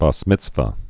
(bäs mĭtsvə)